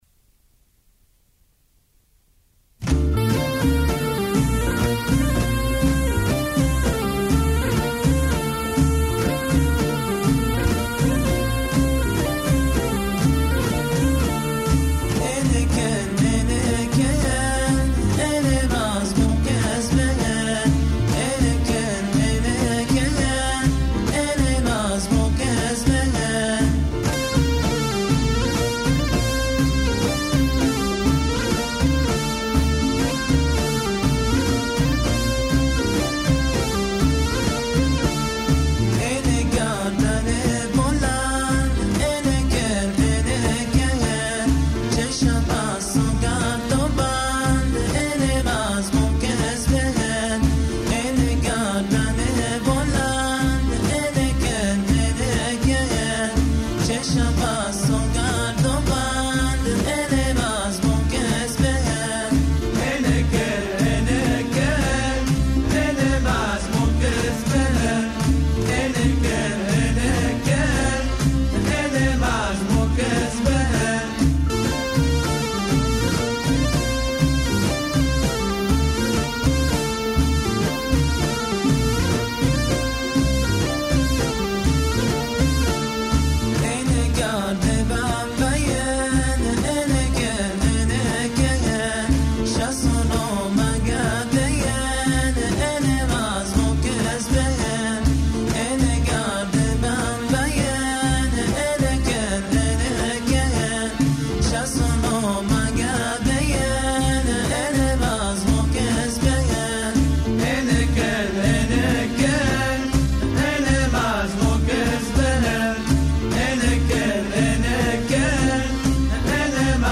اهنگ بستکی دل کهن دل کهن اچم ا صحرا و بهار